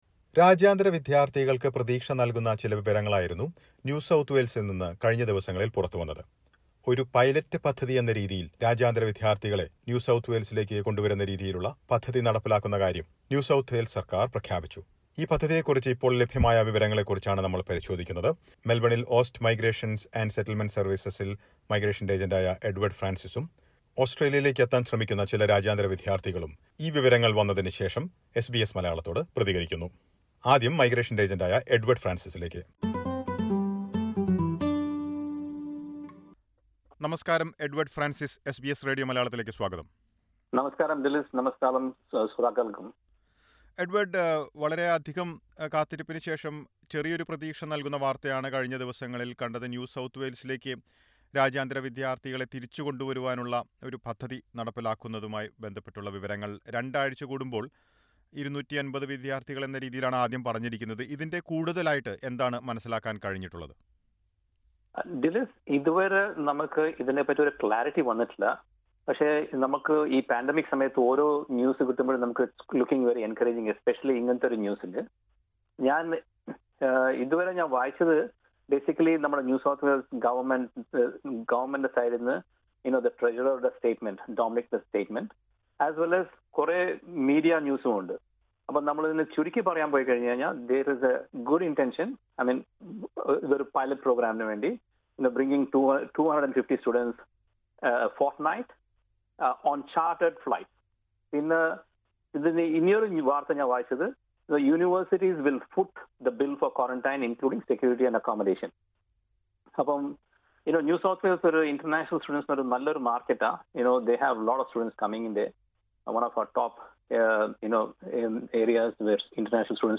NSW has announced a pilot plan to bring back international students. But many students waiting for their opportunity are not sure when they can make it to Australia. Listen to a report